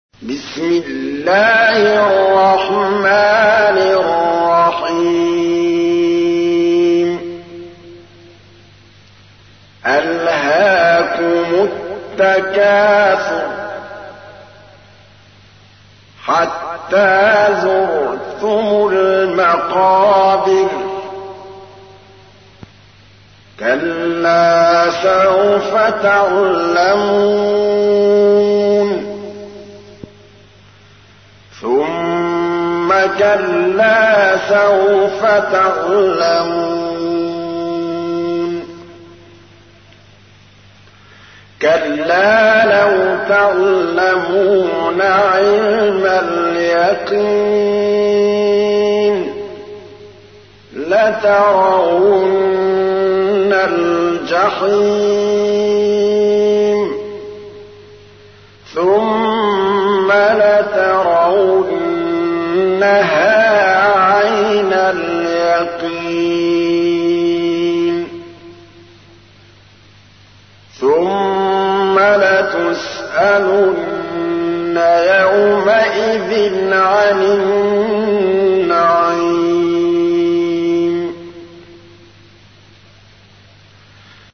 تحميل : 102. سورة التكاثر / القارئ محمود الطبلاوي / القرآن الكريم / موقع يا حسين